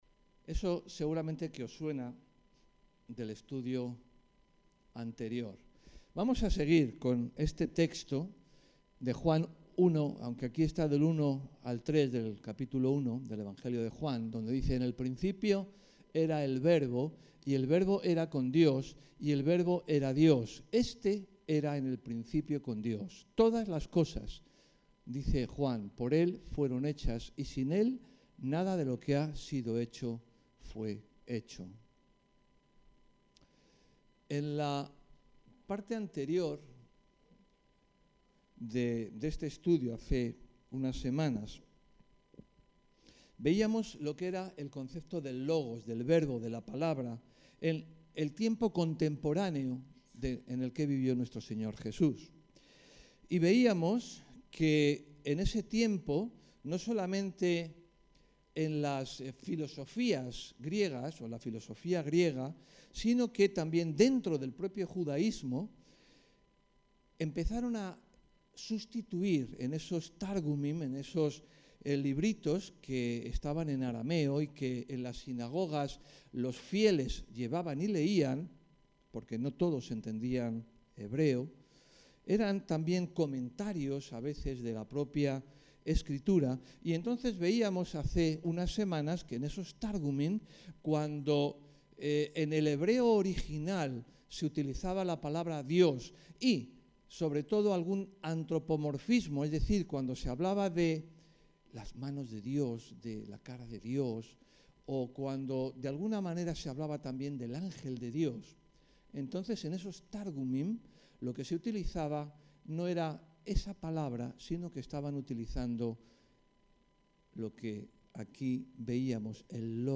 El material de apoyo a la predicación está aquí: Juan Perlas 02 – El Logos II